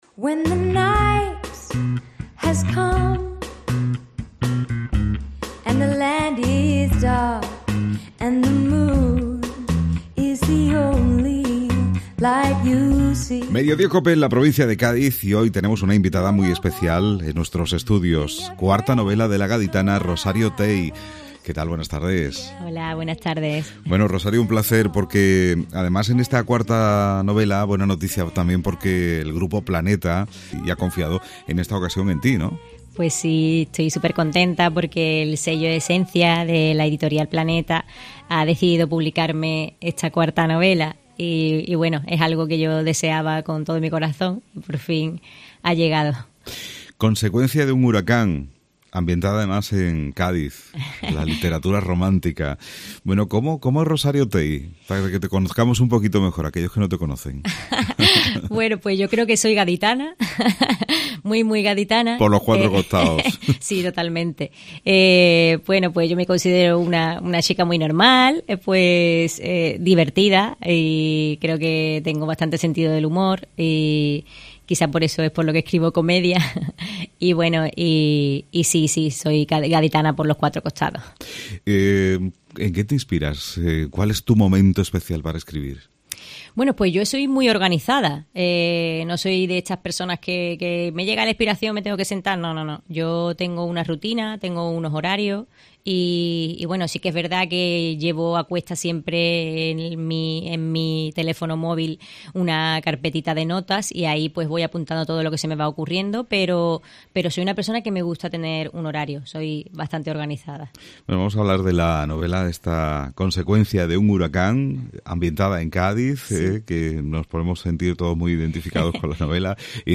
Entrevista en COPE Cádiz sobre "Consecuencias de un huracán"